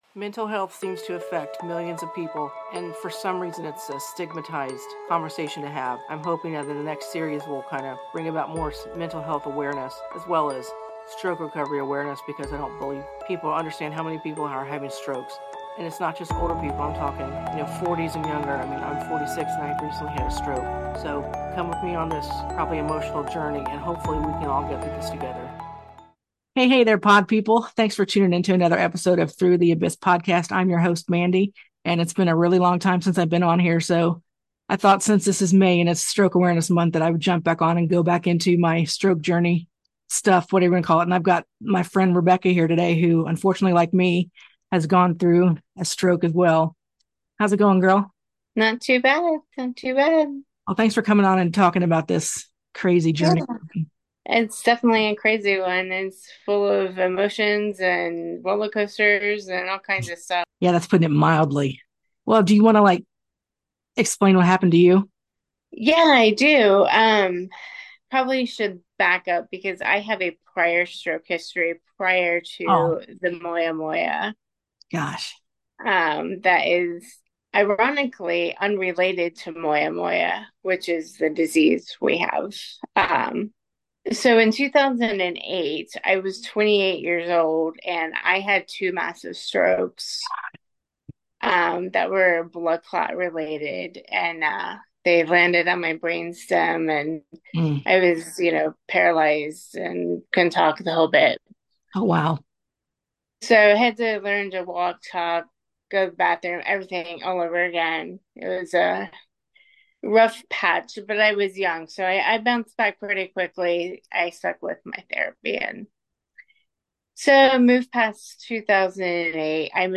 IM TALKING TO A FRIEND OF MINE WHO ALSO HAD A STROKE, DISCUSSING THE MOST DIFFICULT THINGS WE DEAL WITH